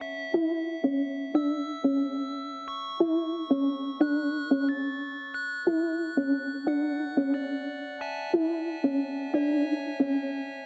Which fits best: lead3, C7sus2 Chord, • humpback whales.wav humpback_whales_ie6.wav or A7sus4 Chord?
lead3